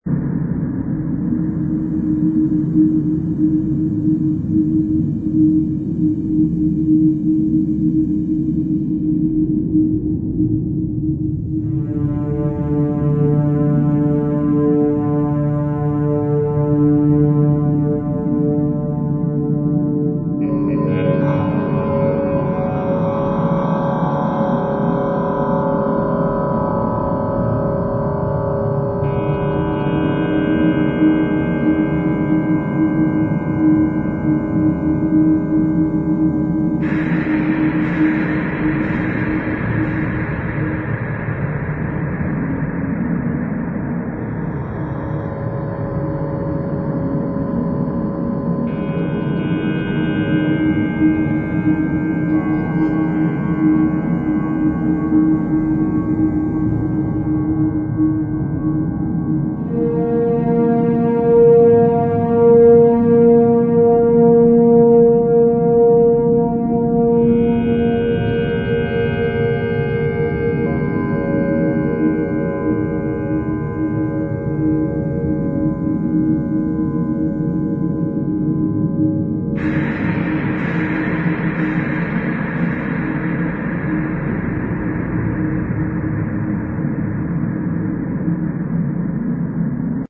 AMBIENCES